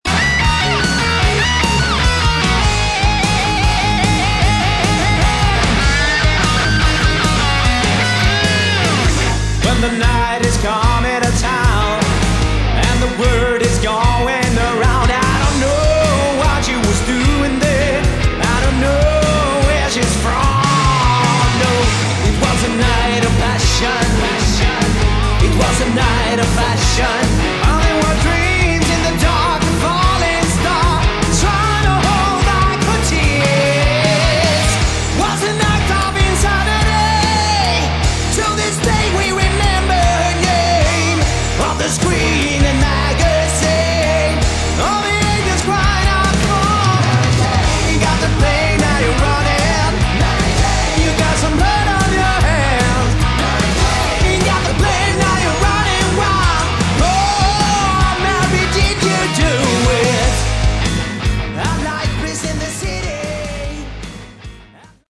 Category: Hard Rock / Melodic Metal
vocals
lead guitars
bass
drums